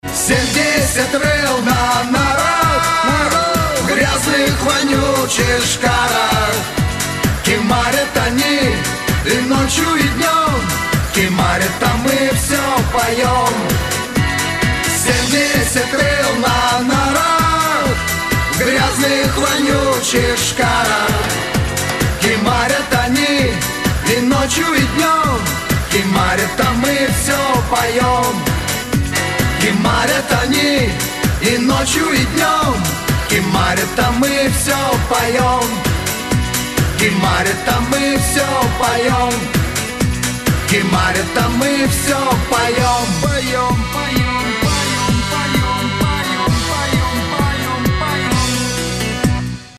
Категория: Нарезки шансона